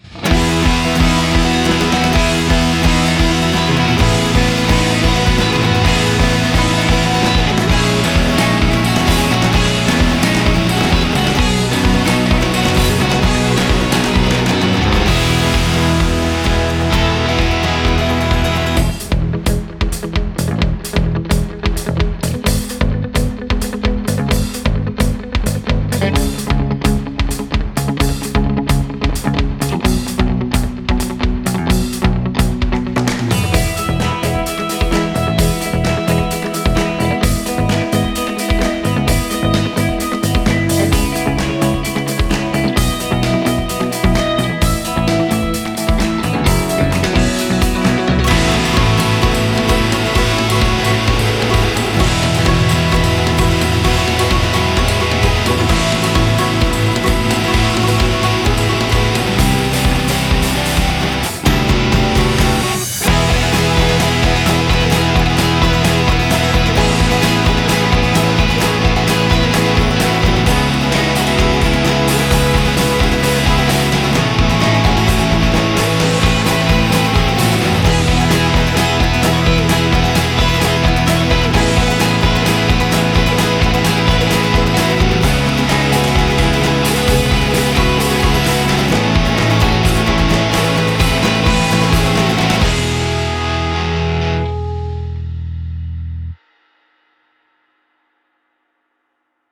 あかるい